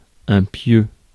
Ääntäminen
IPA: /pjø/